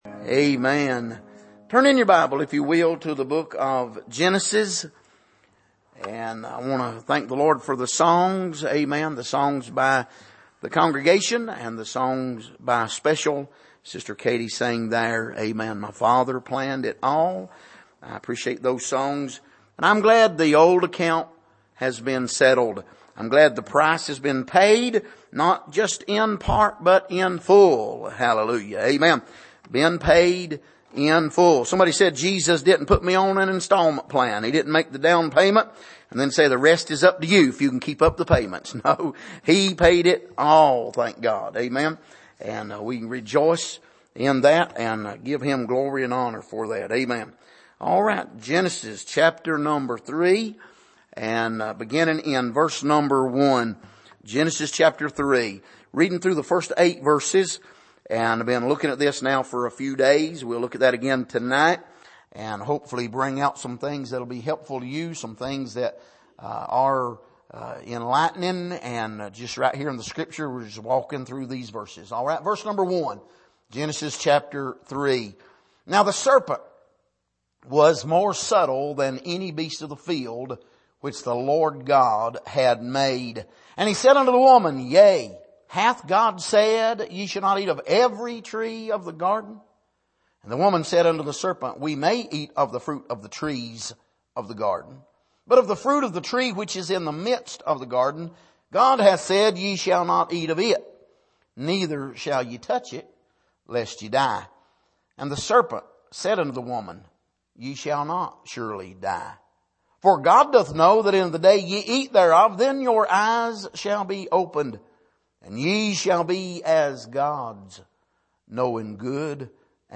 Miscellaneous Passage: Genesis 3:1-8 Service: Midweek